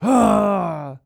Male_Grunt_Attack_02.wav